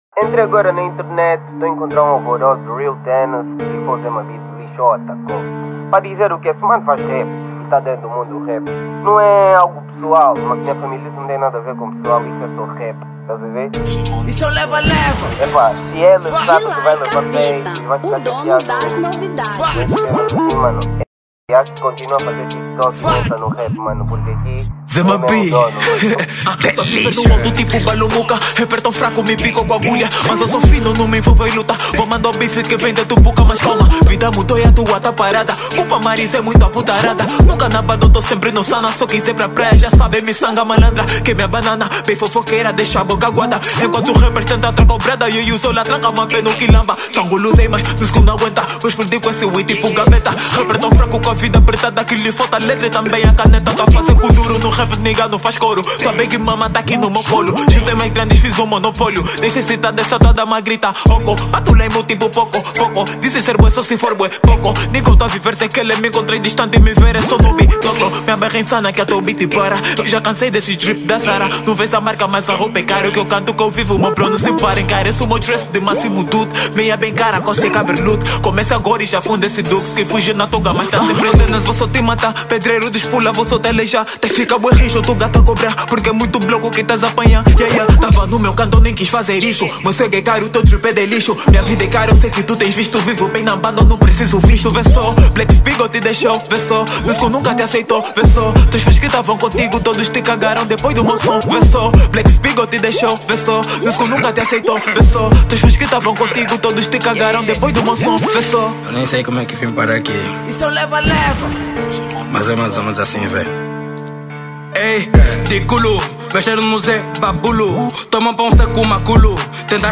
Rap 2025